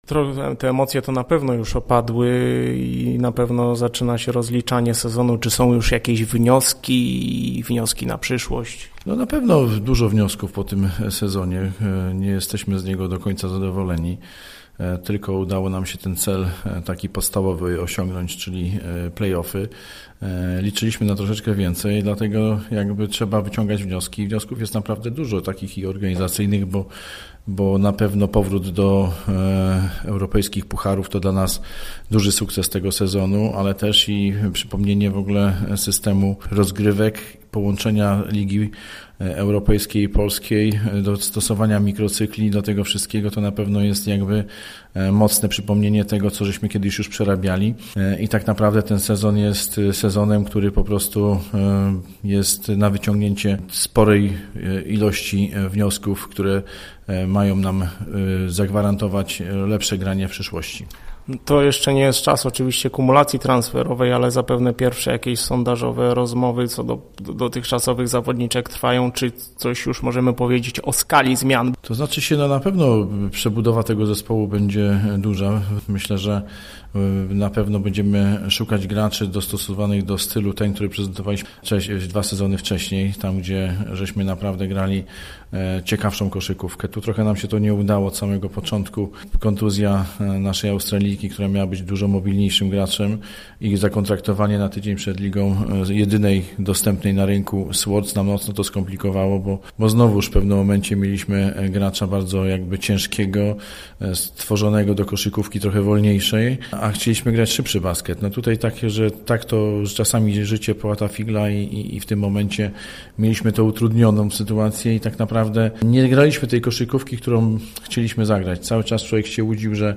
Wiadomo już, że drużyna przed kolejnym sezonem przejdzie istotne zmiany. W tej chwili zapadła decyzja o pożegnaniu z czterema dotychczasowymi zawodniczkami. Szczegóły w rozmowie